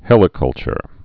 (hĕlĭ-kŭlchər, hēlĭ-)